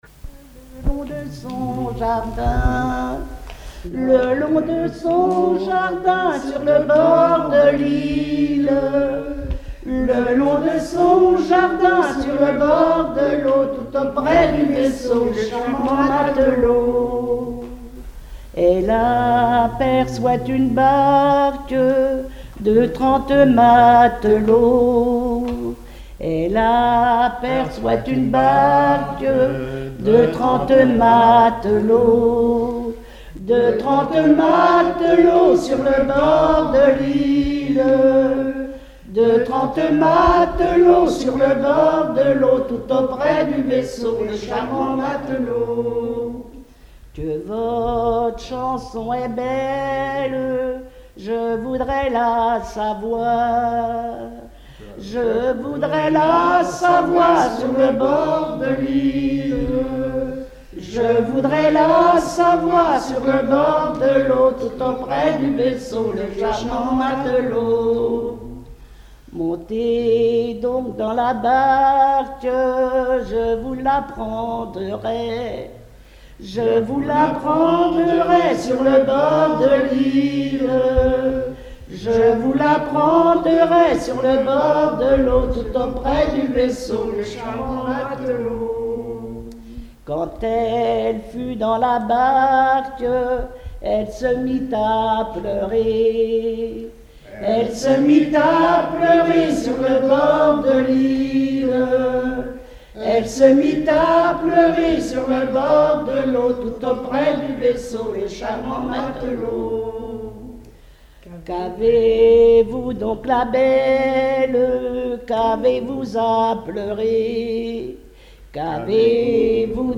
Genre laisse
Chansons et commentaires
Catégorie Pièce musicale inédite